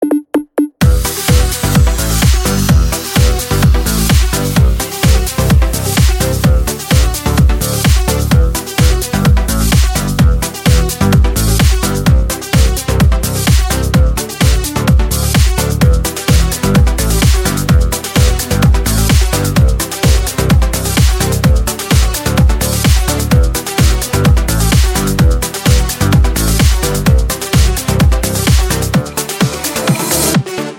• Качество: 256, Stereo
без слов
веселый звонок
Веселый клубный звонок